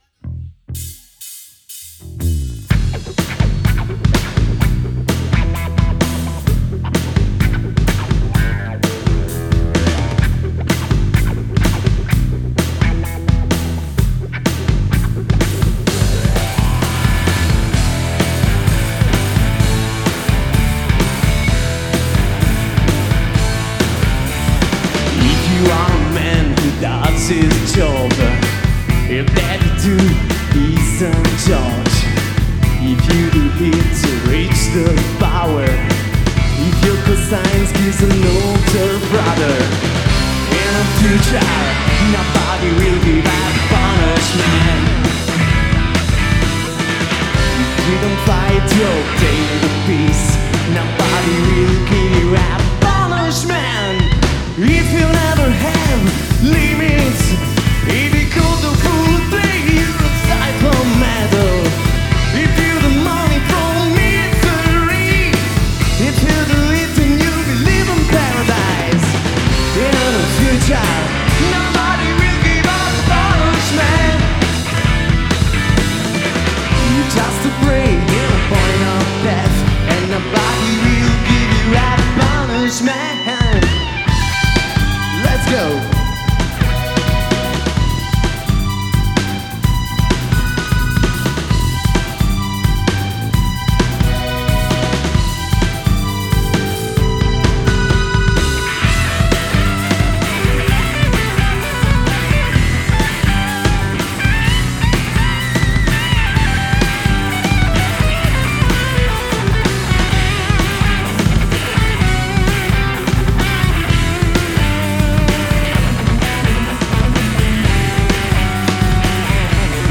Voce e flauto
Chitarra Solista e voce
Chitarra Ritmica
Tastiere e voce
Basso e voce
Batteria